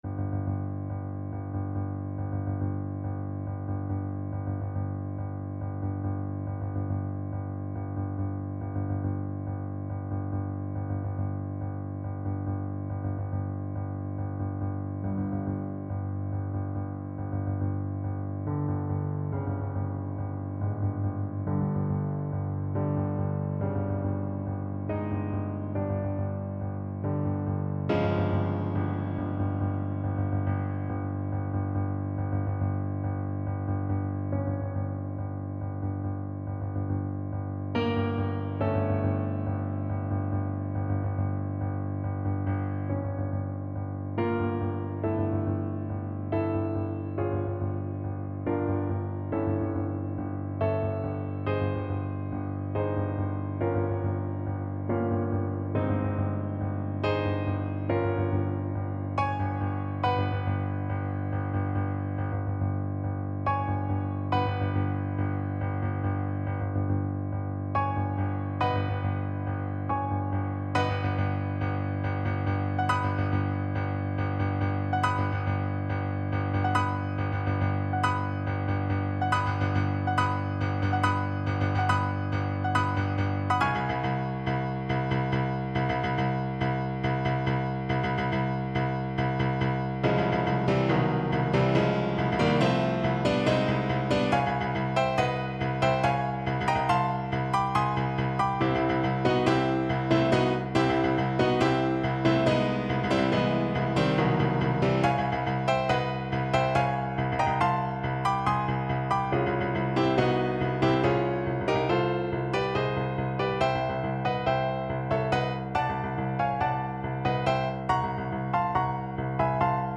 Play (or use space bar on your keyboard) Pause Music Playalong - Piano Accompaniment Playalong Band Accompaniment not yet available transpose reset tempo print settings full screen
5/4 (View more 5/4 Music)
A minor (Sounding Pitch) (View more A minor Music for Flute )
Allegro = 140 (View more music marked Allegro)